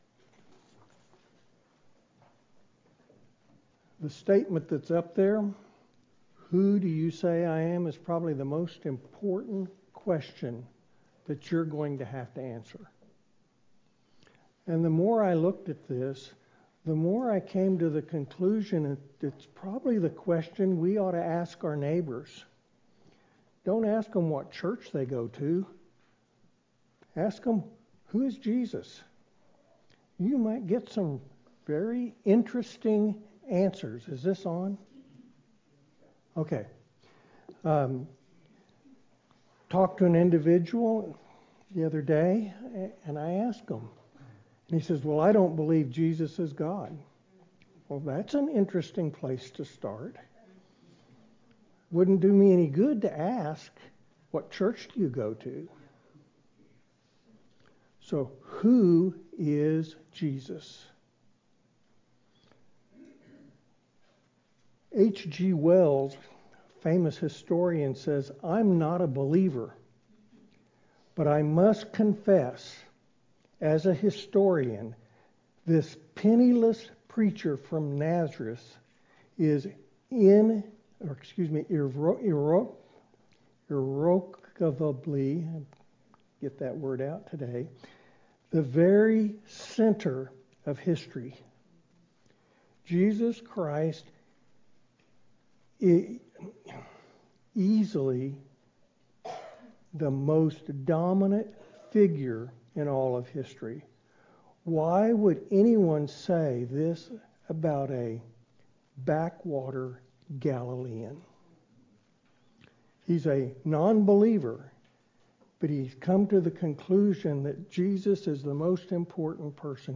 Who-Do-You-Say-That-I-Am-Sermon-CD.mp3